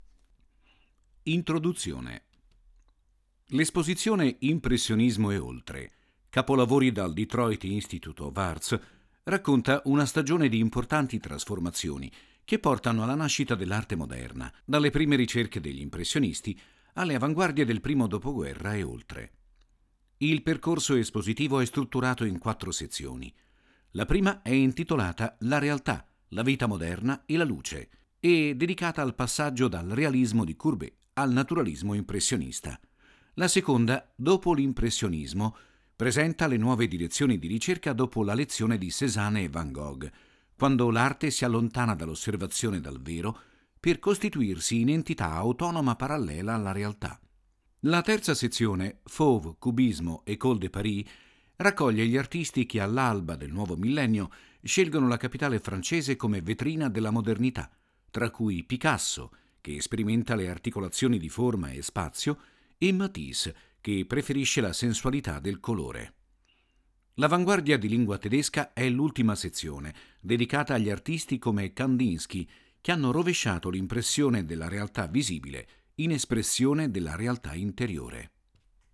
• 5 AUDIODESCRIZIONI DI SEZIONE che accompagnano il visitatore nel percorso espositivo, fruibili tramite QR code
Audiodescrizioni